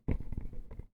new_camera bumb 1.wav